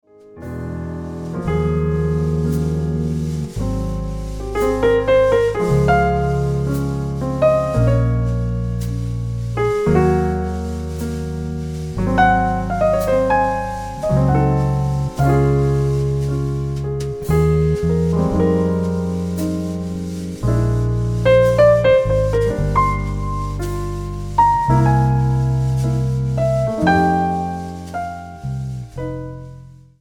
常に新しい始まりを魅せる比類のないピアノトリオのサウンドは、聴き込むほどに魅了される。
心洗われるような雰囲気のナンバー。